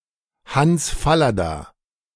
Hans Fallada (German: [hans ˈfa.la.da]